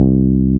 Double Bass (JW2).wav